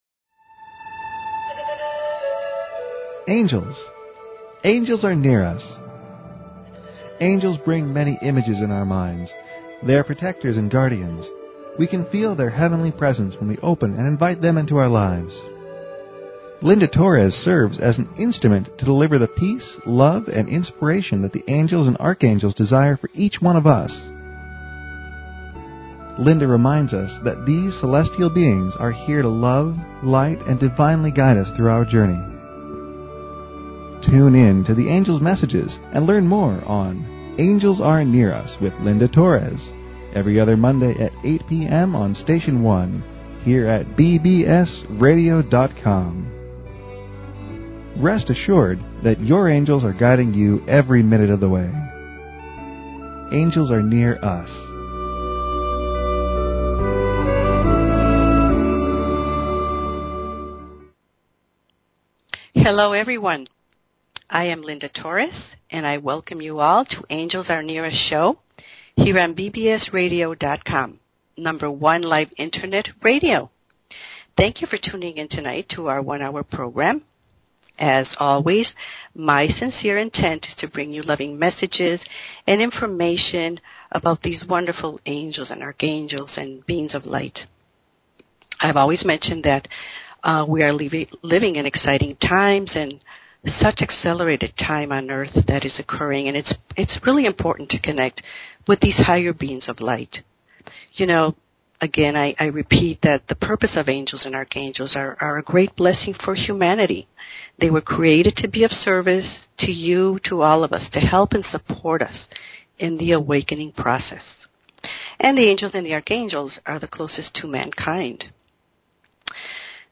Talk Show Episode, Audio Podcast, Angels_Are_Near_Us and Courtesy of BBS Radio on , show guests , about , categorized as
April 26, 2010 8 pm PT ANGELS ARE NEAR US - Live Show
The last 30 minutes of the show the phone lines will be open for questions and Angel readings.